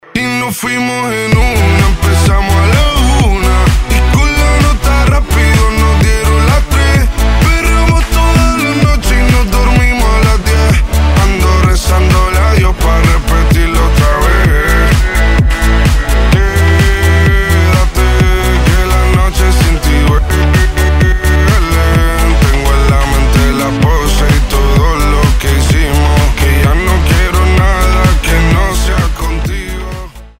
pop rap
Танцевальный рэп